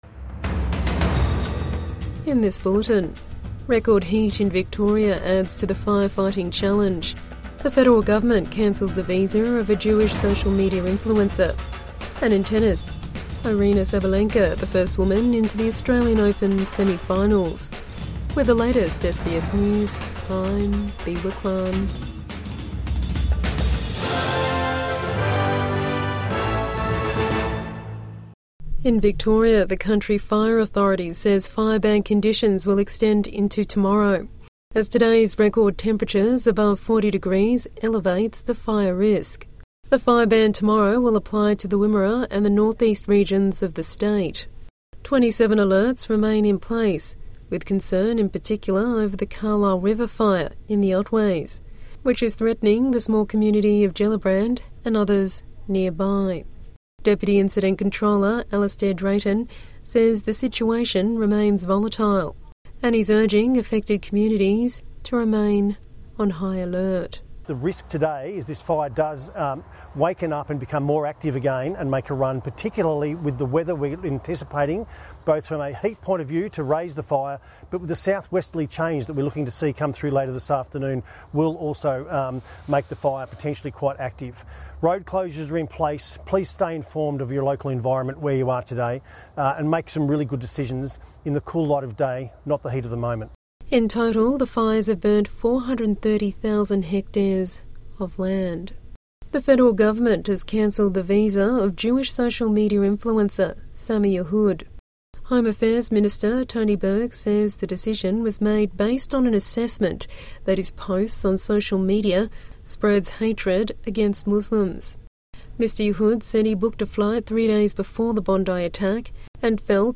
Listen to Australian and world news and follow trending topics with SBS News Podcasts.